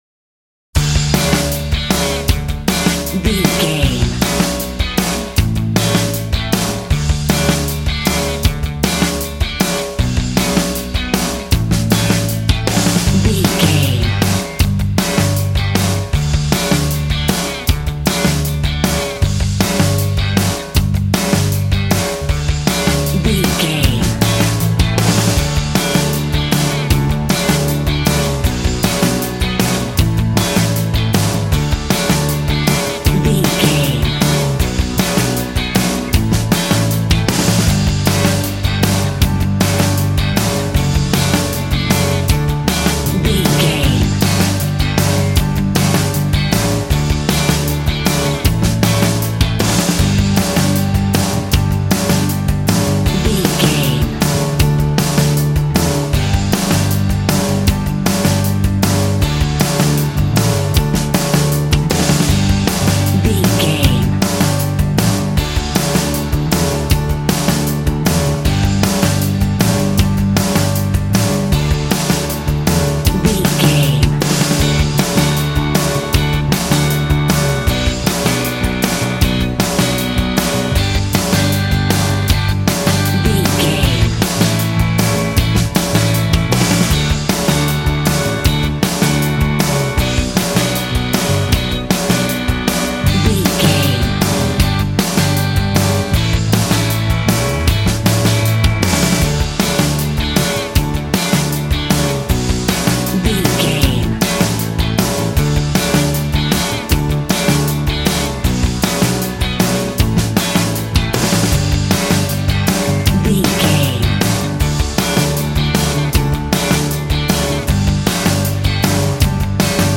Ionian/Major
D
groovy
powerful
fun
organ
drums
bass guitar
electric guitar
piano